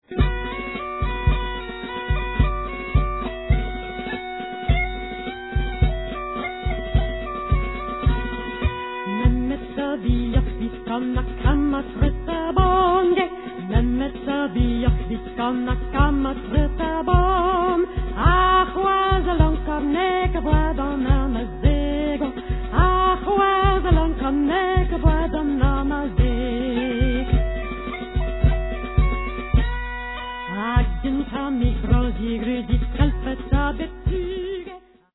French and Breton folk songs